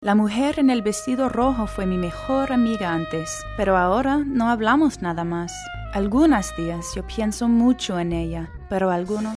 Sprecherin englisch.
Sprechprobe: Industrie (Muttersprache):